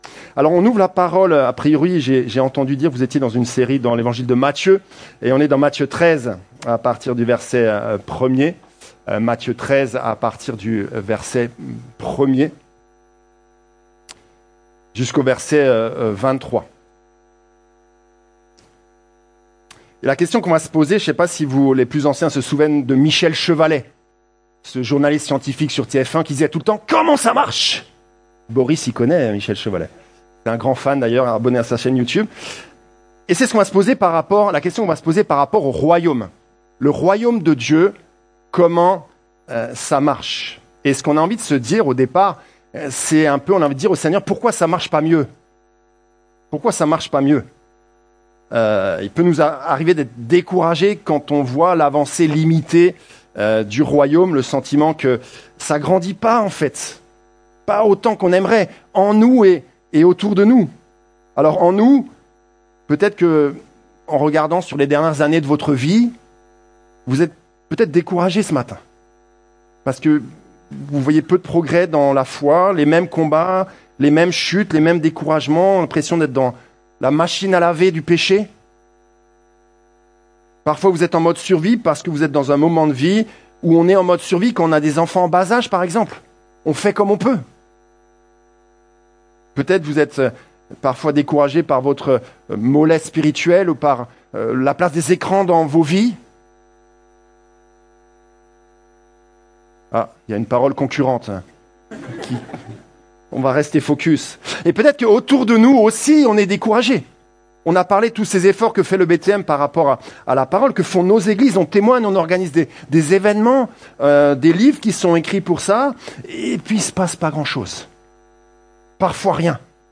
Sermons - Église Baptiste Toulouse Métropole